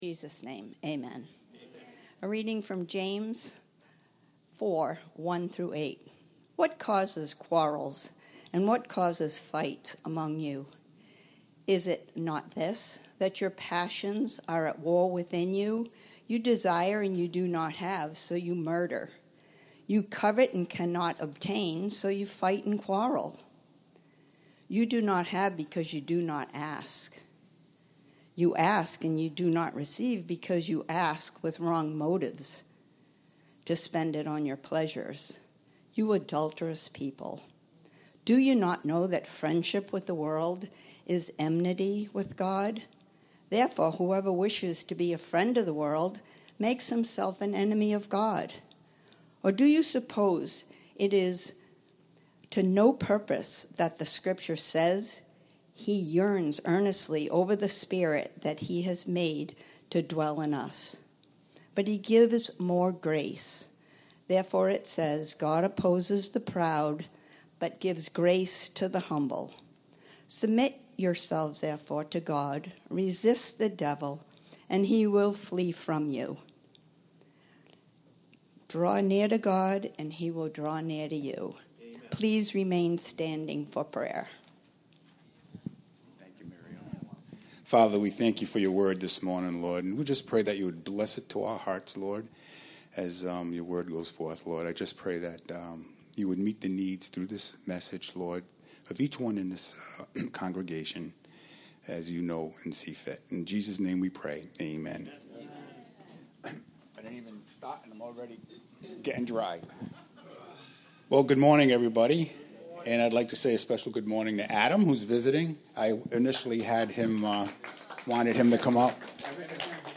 Sermons – All – Harvest Community Church